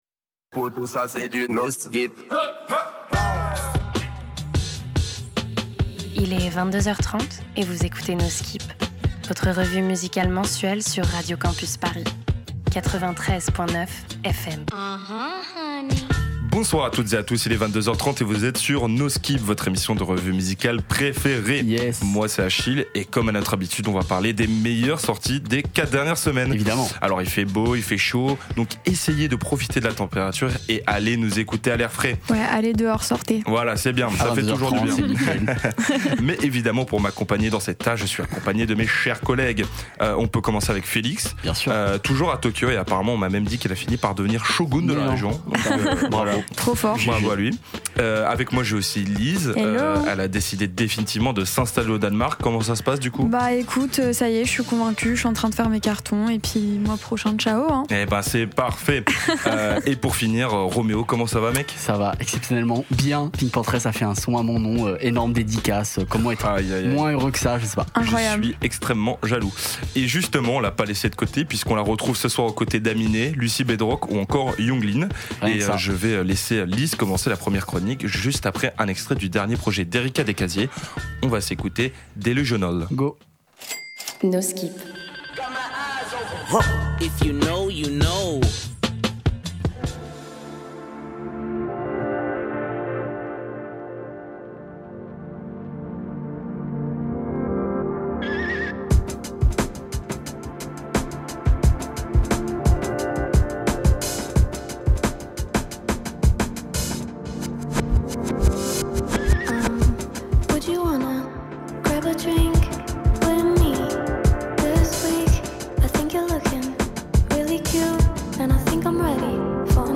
Découvertes musicales Électro Éclectique